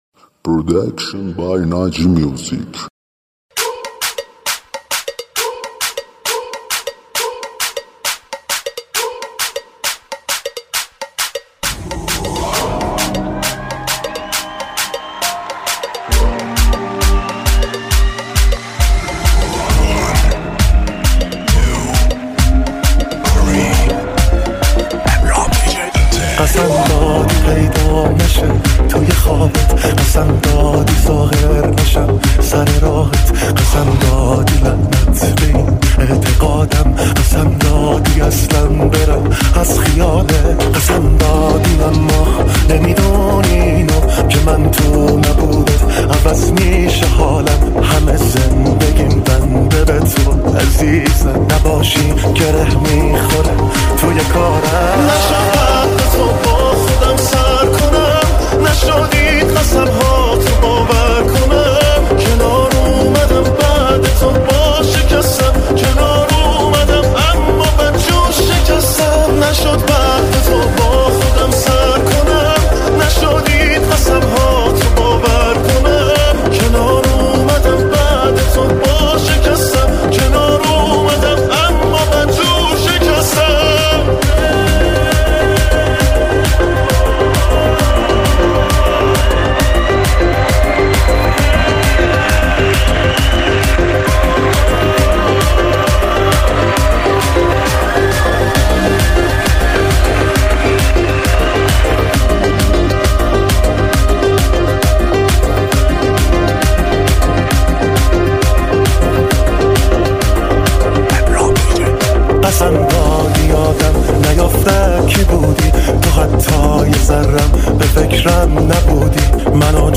آهنگ شاد تریبال
آهنگ شاد تریبال مخصوص پارتی و رقص